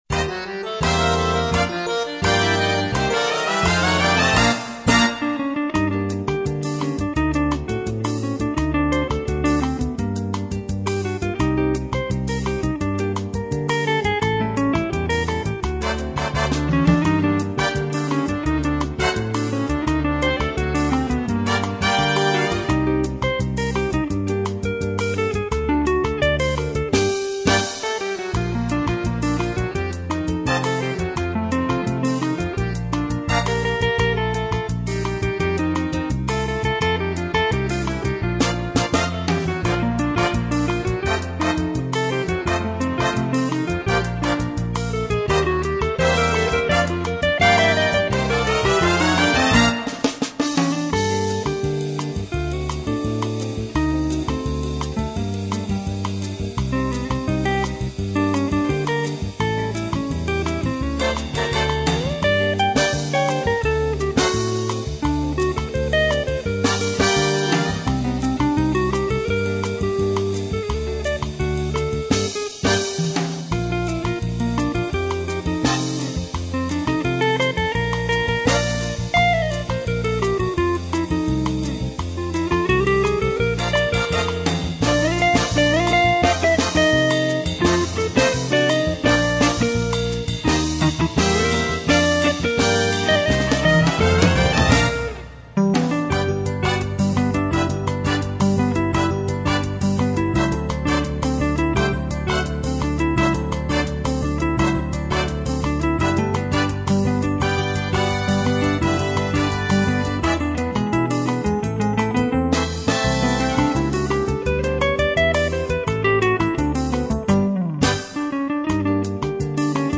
本来はアップテンポですが私の技量では無理なのでのんびり弾きました。